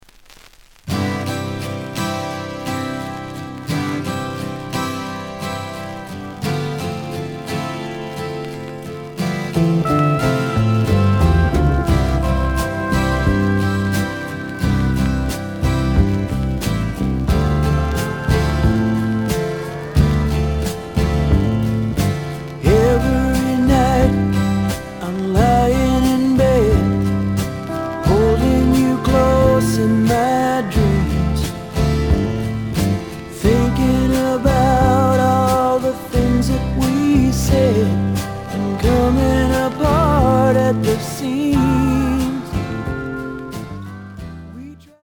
The audio sample is recorded from the actual item.
●Format: 7 inch
●Genre: Rock / Pop
Looks good, but slight noise on both sides.)